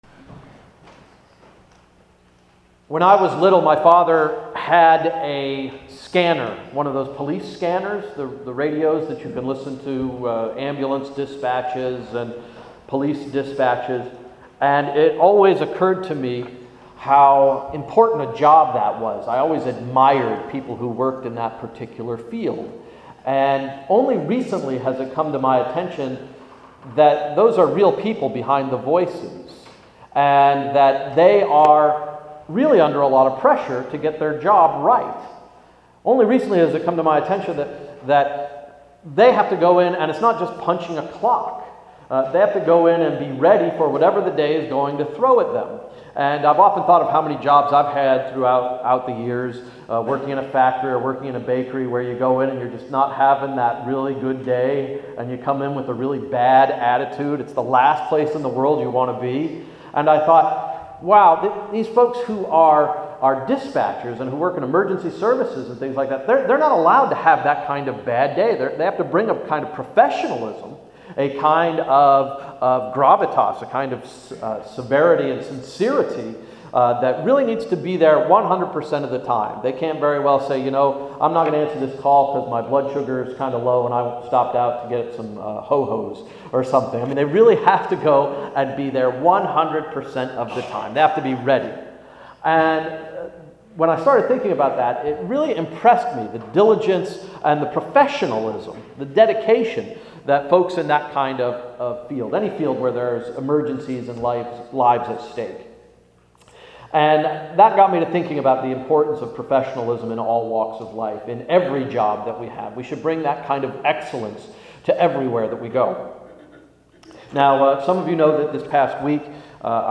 Sermon of May 13, 2012–“The Law of Love”